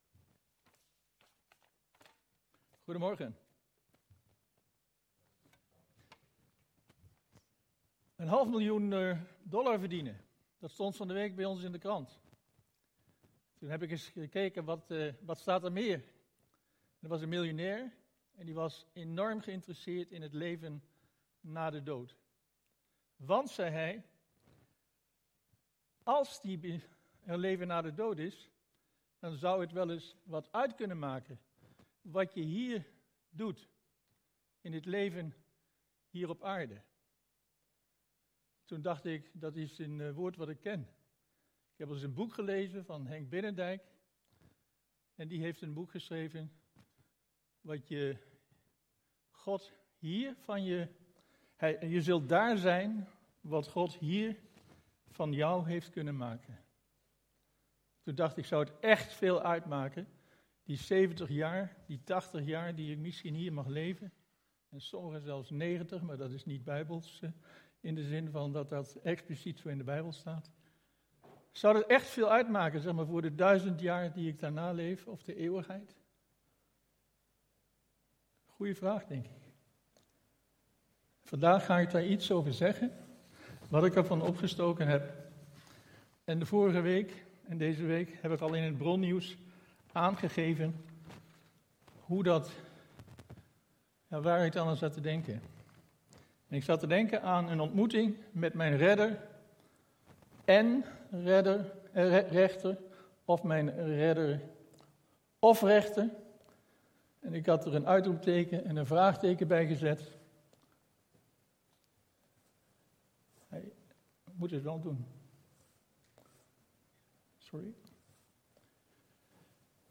Toespraak 31 januari: ontmoeting met mijn Redder en Rechter!